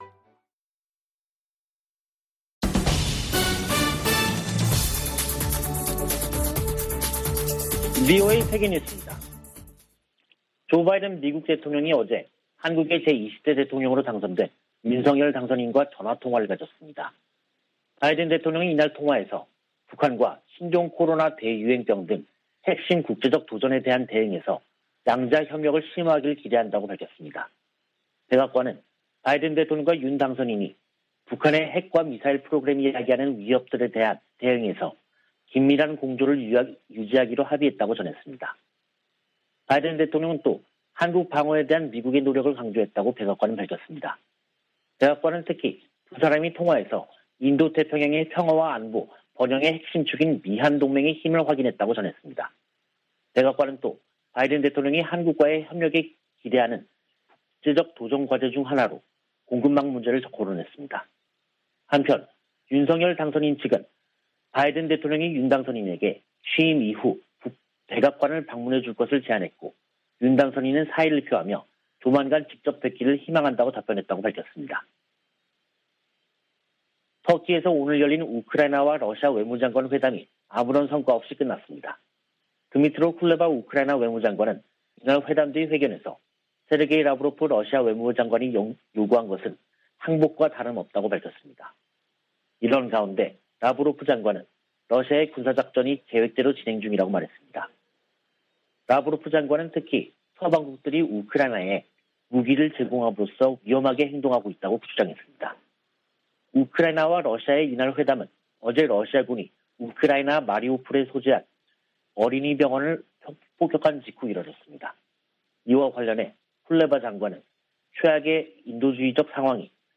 VOA 한국어 간판 뉴스 프로그램 '뉴스 투데이', 2022년 3월 10일 3부 방송입니다. 한국 대통령 선거에서 윤석열 후보가 승리했습니다.